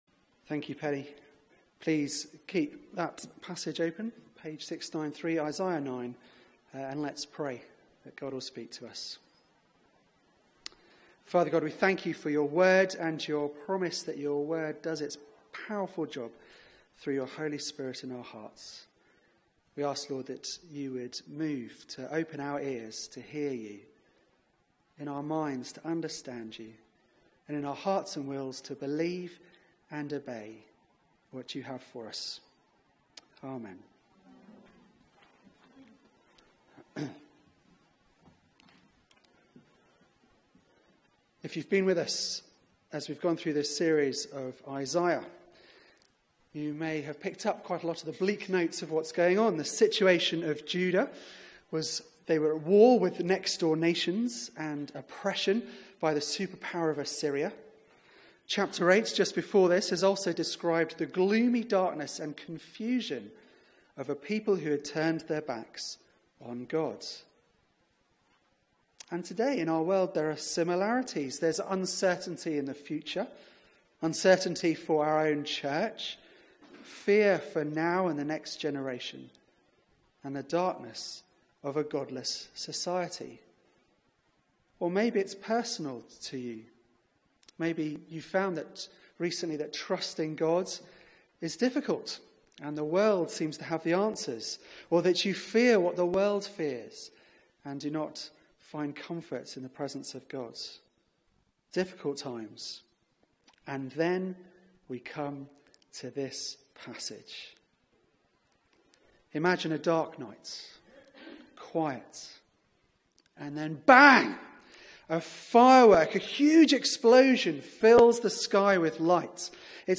Media for 4pm Service on Sun 19th Nov 2017 16:00 Speaker